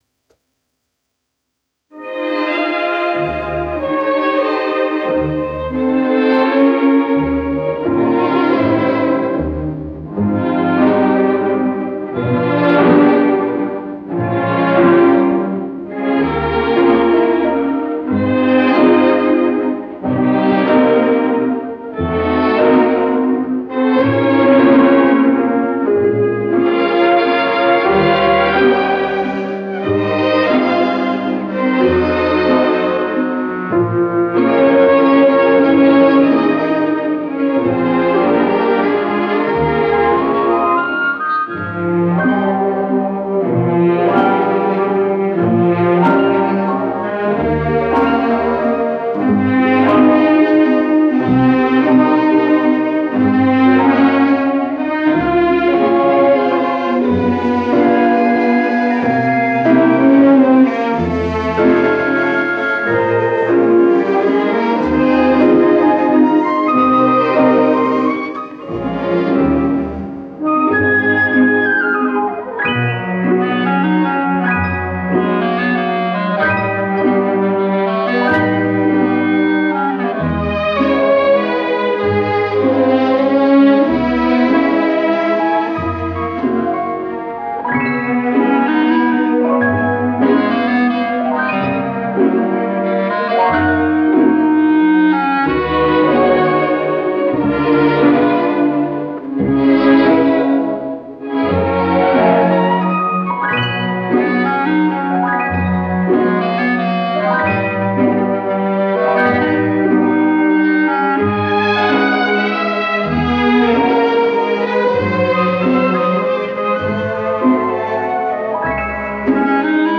медленный вальс
Вот почищенные от шума варианты.